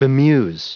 Prononciation du mot bemuse en anglais (fichier audio)
Prononciation du mot : bemuse